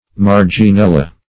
Search Result for " marginella" : The Collaborative International Dictionary of English v.0.48: Marginella \Mar`gi*nel"la\, prop. n. [NL., dim. of L. margo, marginis, a margin.]